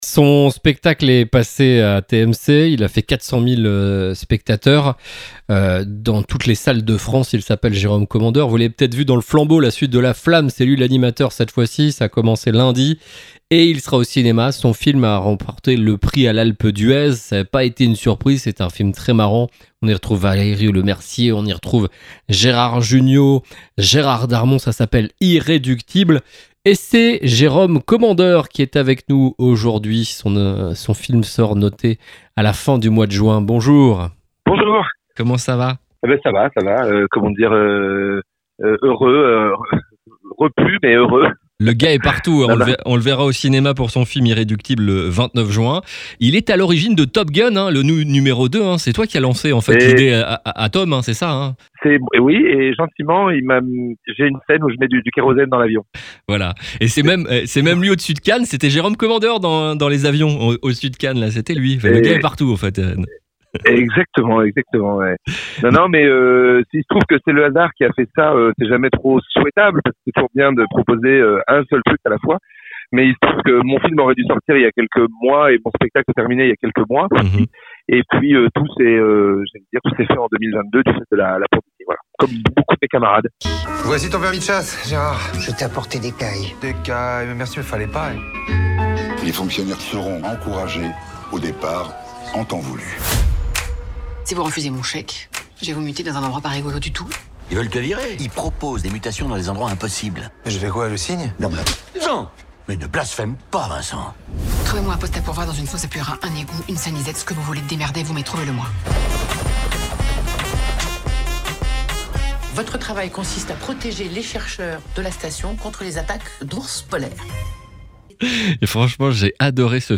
Jérôme Commandeur était notre invité, mercredi 25 mai ! Il est venu nous parler de son nouveau film “Irréductible” qui sort le 29 juin 2022 !